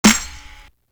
Dub Clap.wav